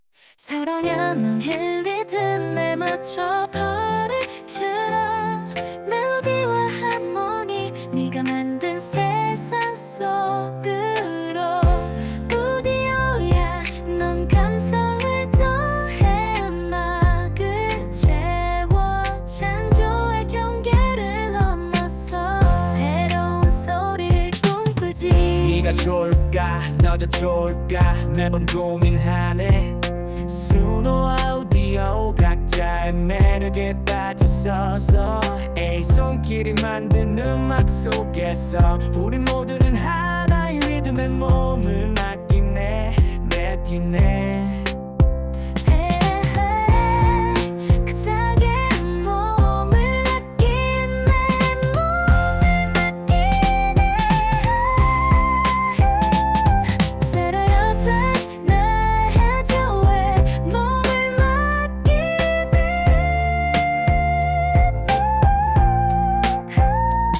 수노에서는 케이팝, 힙합, 랩 등 다양한 장르를 조합하여 음악을 만들었고, 우디오에서는 케이팝 장르가 아직 지원되지 않는 것 같아 동일하게 하려 했지만 코리안 발라드와 힙합, 랩을 조합하여 노래를 만들었봤습니다.